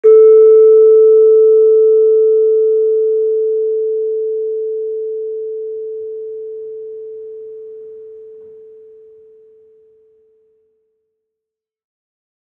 Gamelan Sound Bank
Gender-2-A3-f.wav